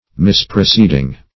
Misproceeding \Mis`pro*ceed"ing\, n. Wrong or irregular proceding.